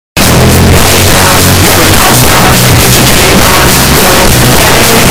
shrek-ear-rape-audio-cutter.mp3